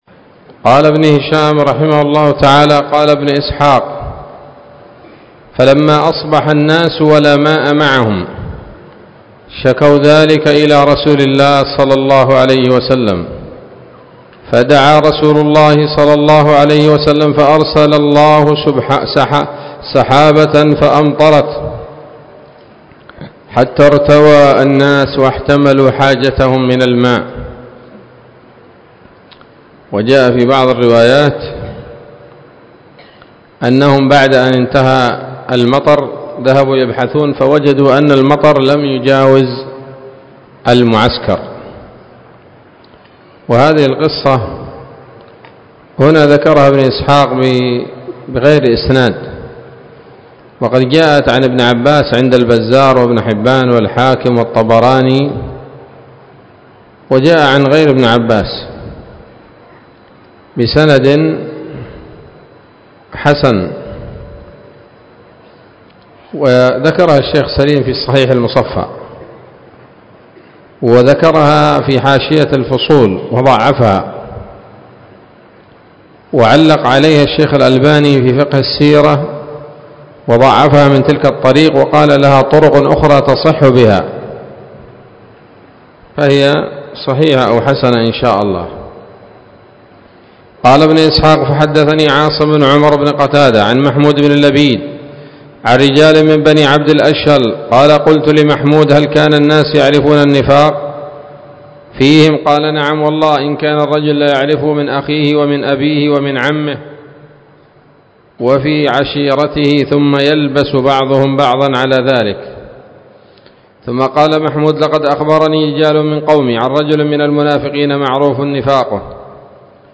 الدرس الرابع والثمانون بعد المائتين من التعليق على كتاب السيرة النبوية لابن هشام